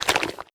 slime8.wav